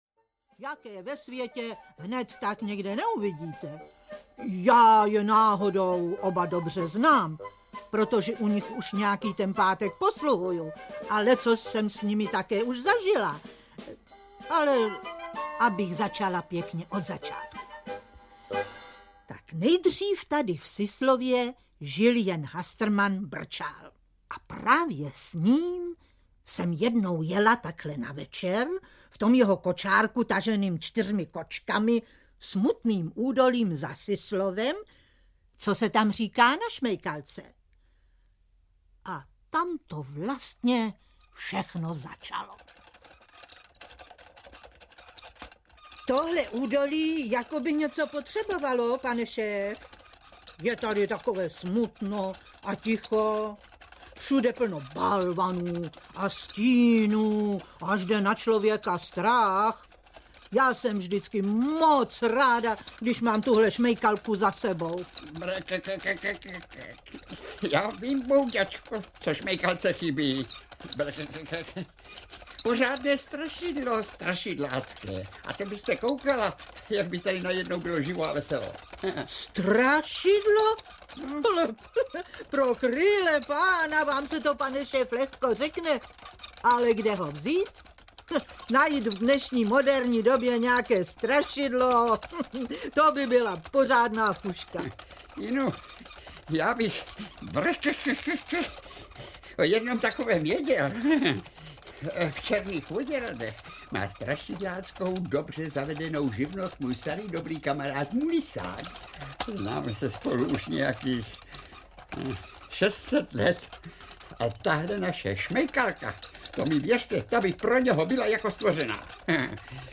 Bubáci a hastrmani audiokniha
Pohádková audiokniha Bubáci a hastrmani obsahuje nestárnoucí titul, který napsal Josef Lada.
Ukázka z knihy